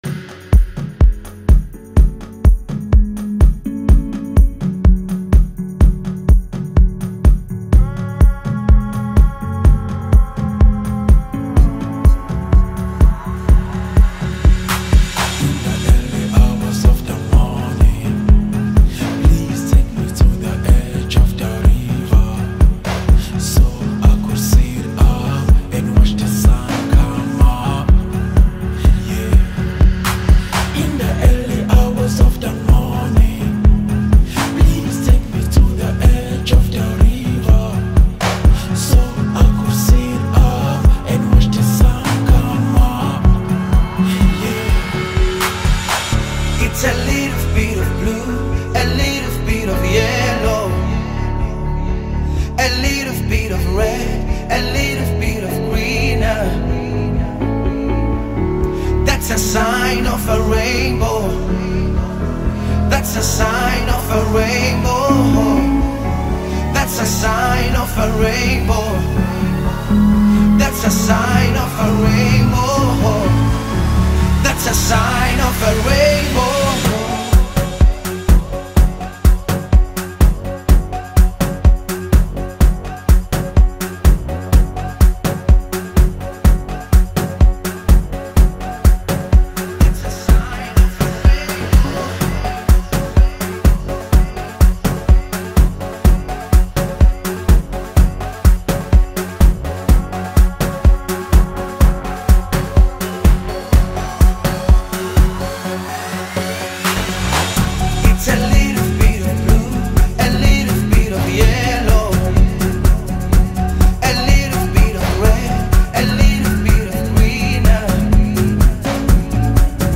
entrancing single
delivered a catchy verse
remix